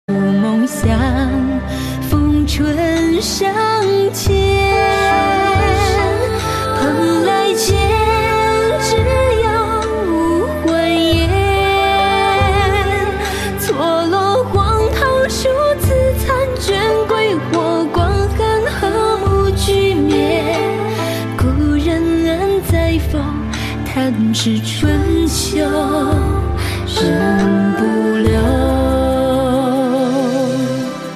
M4R铃声, MP3铃声, 华语歌曲 71 首发日期：2018-05-15 07:51 星期二